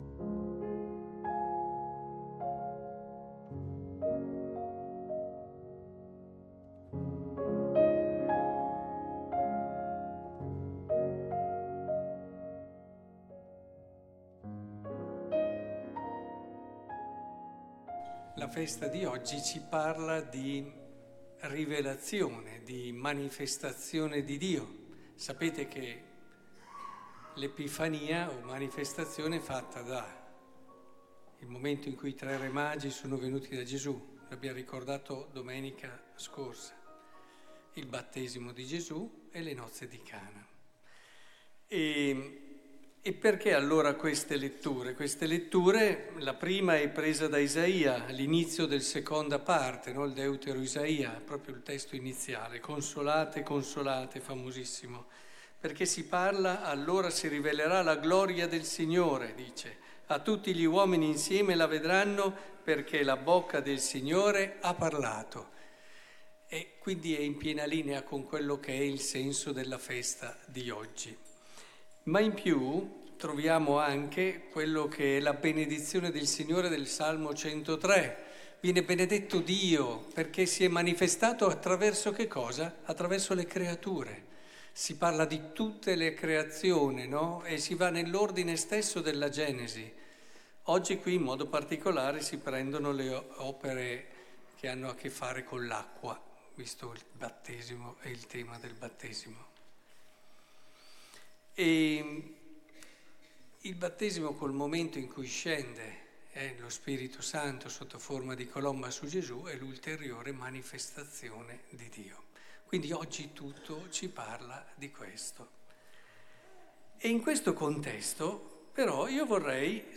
Il Vangelo ci racconta la nostra Bellezza Omelie giorno per giorno.